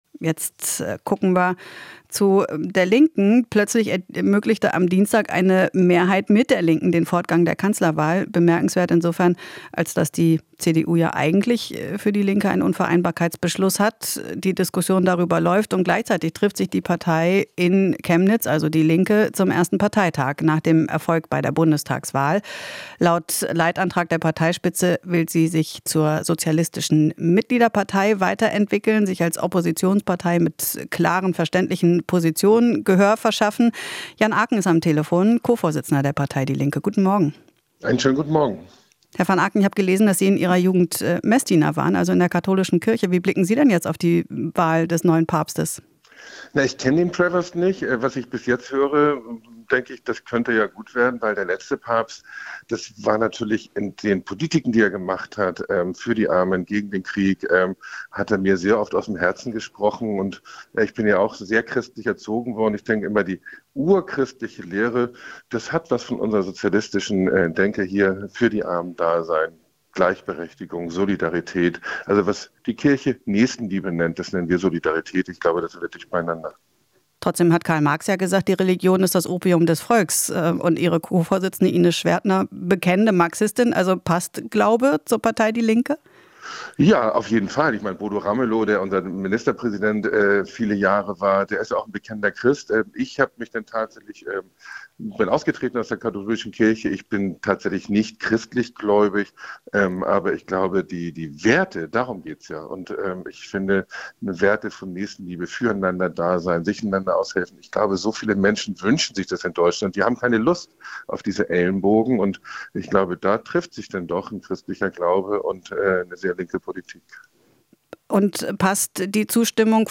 Interview - Jan van Aken (Linke): "Wir wollen die Hoffnung organisieren"